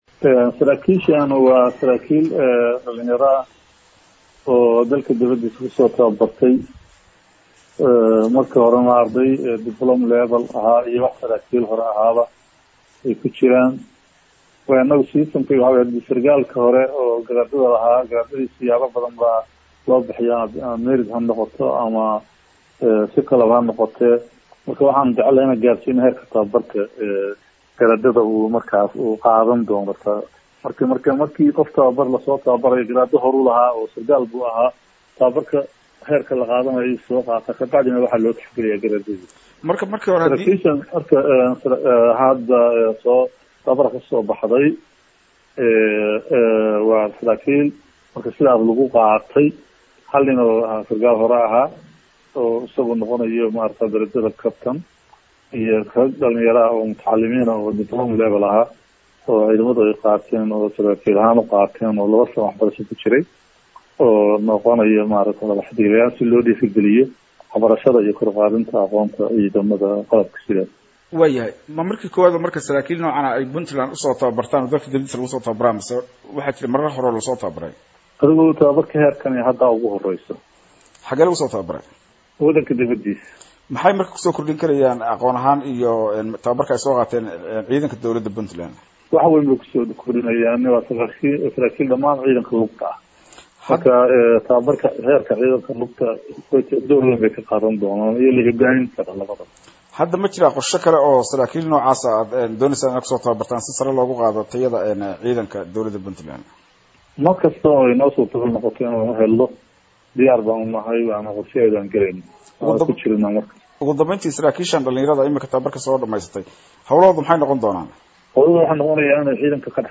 DHEGEYSO-Taliyaha Ciidanka Difaaca Oo Kahadlay Tababarada Ciidanka.